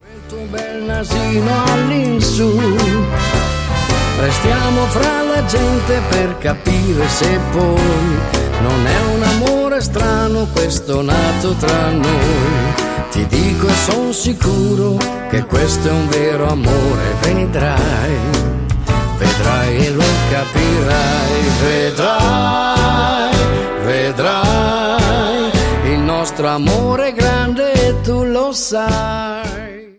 MODERATO SWING  (03,13)